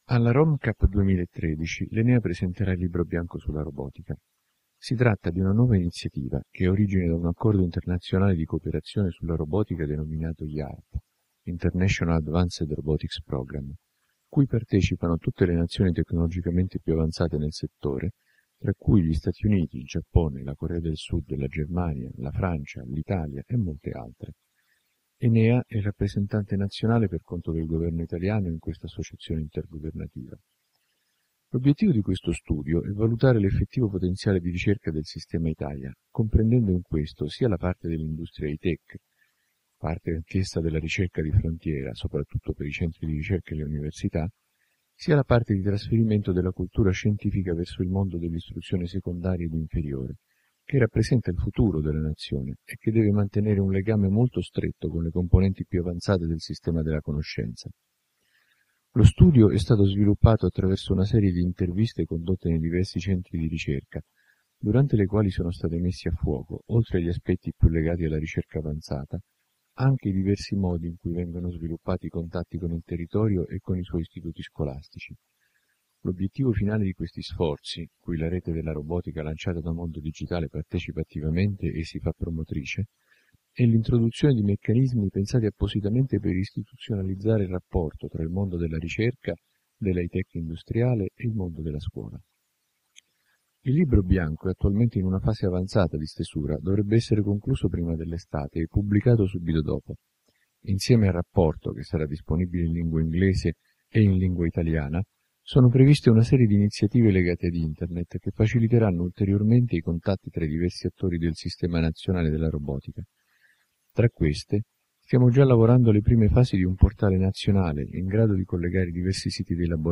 IntervistaRomeCup.mp3